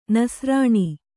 ♪ nasrāṇi